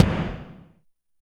12 AMB KIK-R.wav